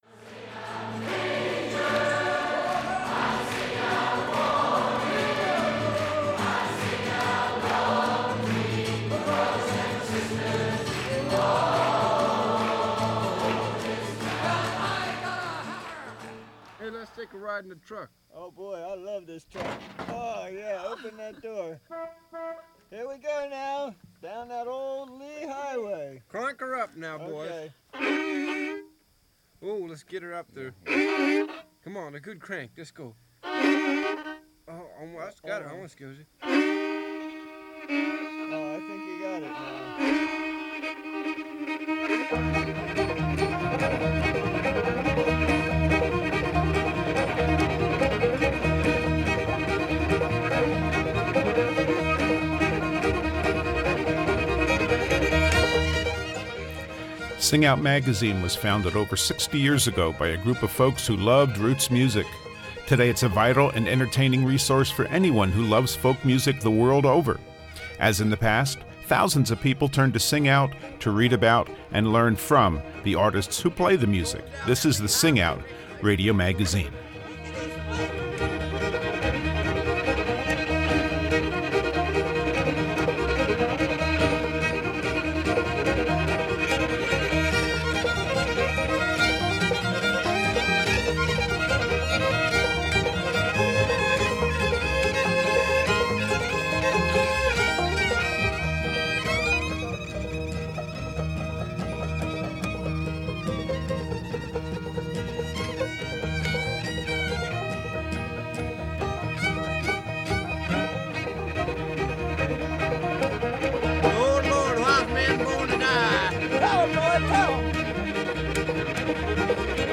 This week we continue our occasional feature focusing on a distinctive style of folk music. It's time to highlight old-time music with selections from the Golden Age of the music through today's finest players.